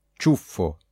Jeśli między c i a, c i o lub c i u znajduję się nieme i (nie wymawiamy go), to c będziemy czytać jak miękkie /cz/: